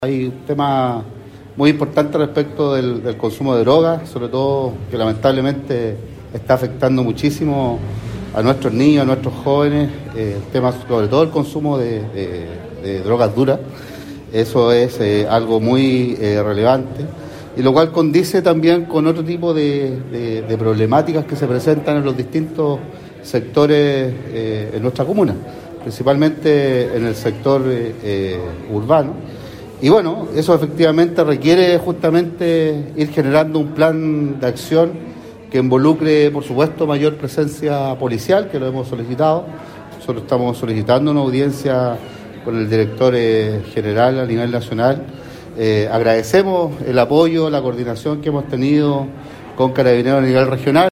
Christian-Gross-Alcalde-de-Los-Vilos.mp3